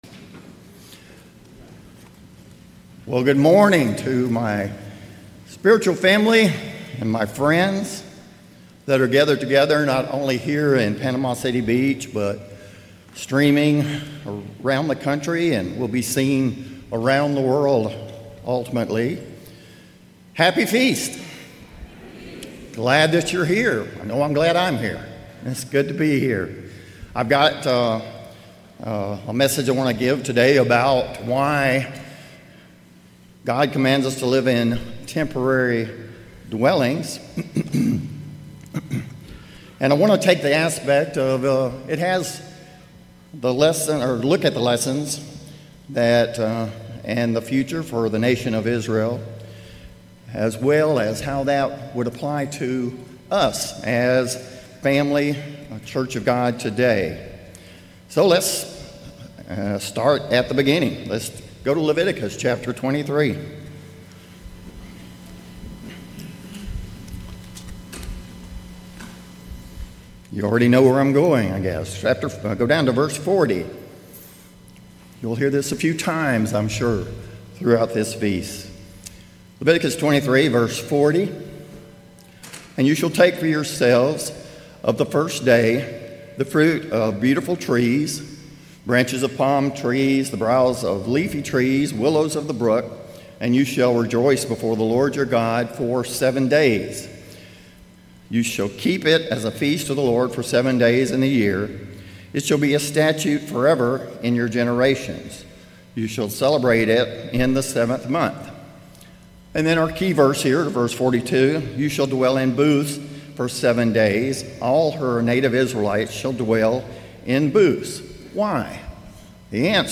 This message was given during the 2024 Feast of Tabernacles in Panama City Beach, Florida.